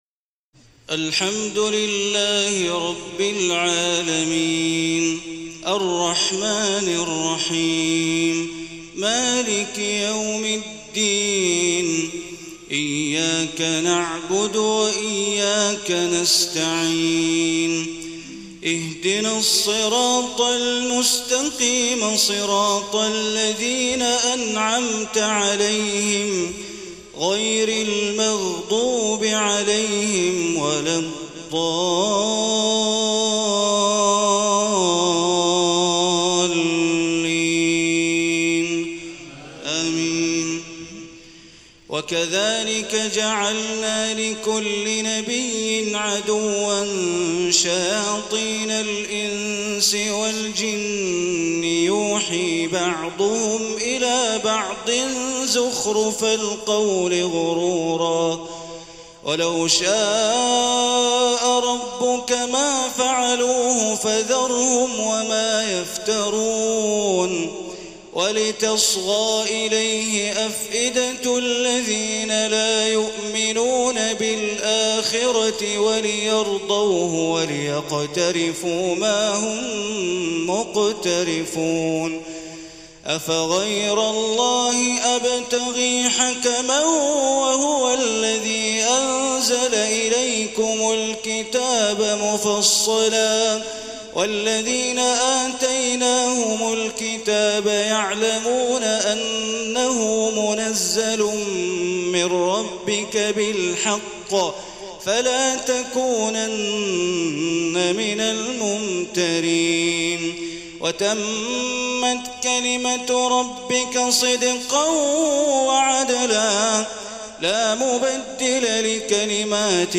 تهجد ليلة 28 رمضان 1434هـ من سورتي الأنعام (112-165) و الأعراف (1-30) Tahajjud 28 st night Ramadan 1434H from Surah Al-An’aam and Al-A’raf > تراويح الحرم المكي عام 1434 🕋 > التراويح - تلاوات الحرمين